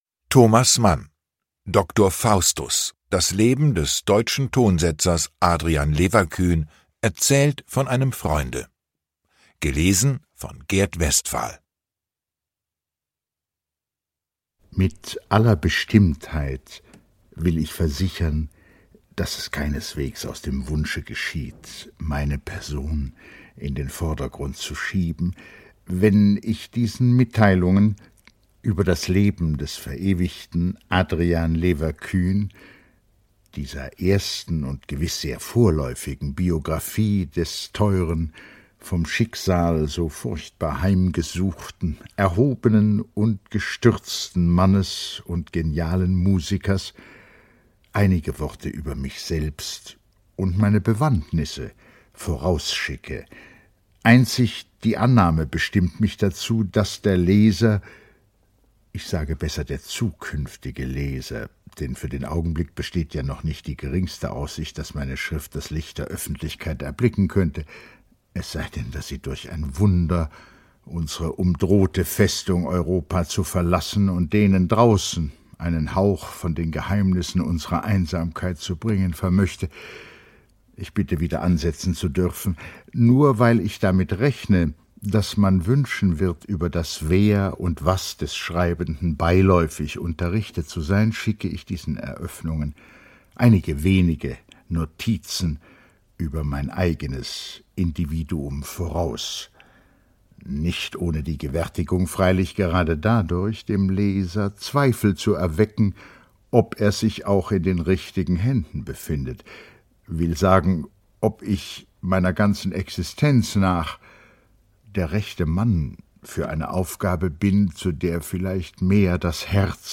Gert Westphal (Sprecher)
Er bringt die feine Ironie und die Prägnanz der Mann’schen Sprache vollendet zum Ausdruck.
Lesung mit Gert Westphal